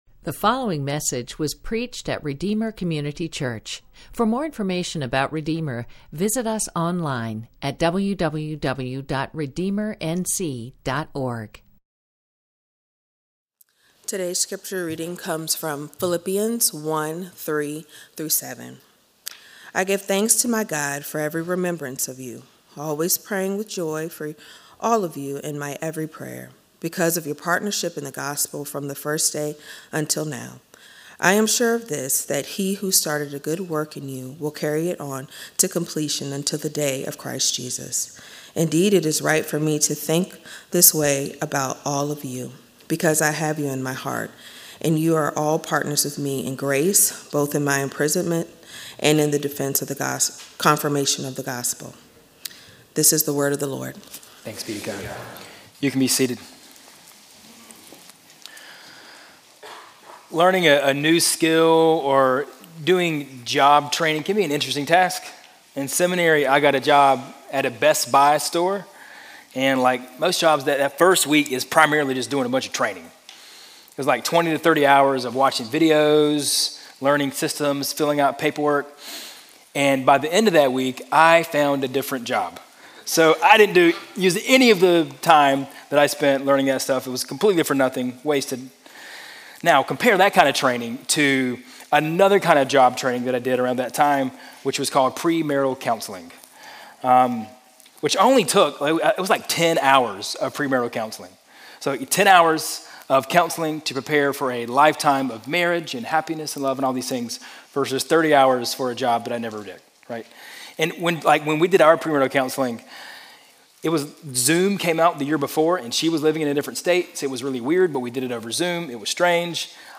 A sermon from the series "Stand-Alone Sermons."